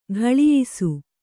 ♪ ghaḷiyisu